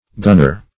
Gunner \Gun"ner\, n.